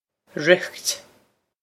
riocht rikt
Pronunciation for how to say
This is an approximate phonetic pronunciation of the phrase.